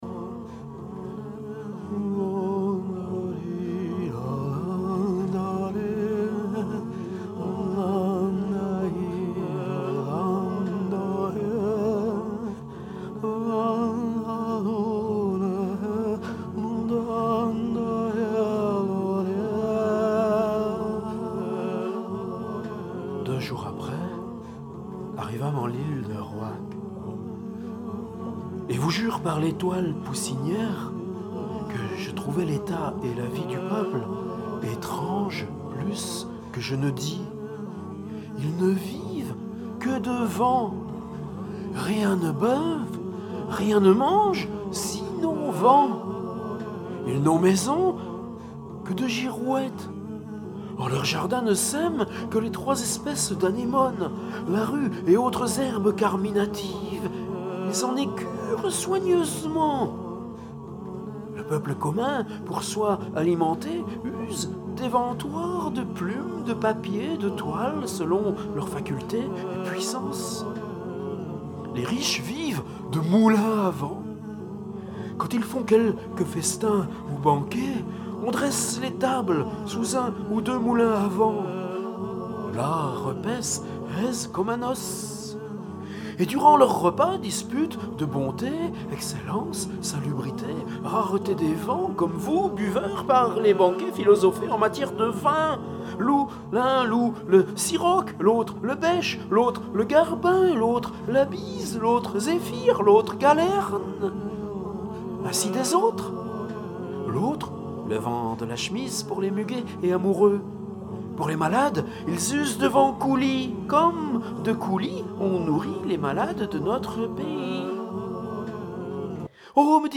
Rabelais à haute voix | le Quart-Livre